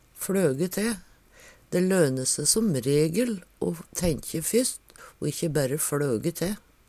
fLøge te - Numedalsmål (en-US)